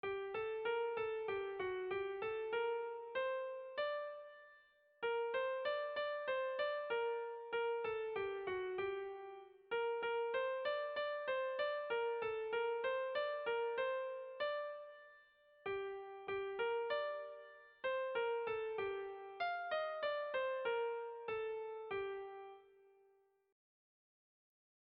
Erromantzea
Seiko handia (hg) / Hiru puntuko handia (ip)
ABD